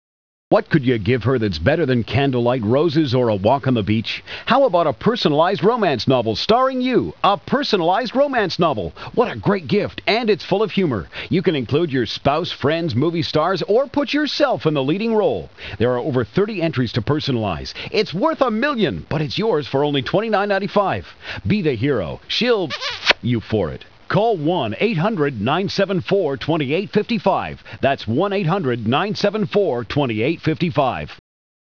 Radio and Podcast Commercials
A great big KISS - 30 second promo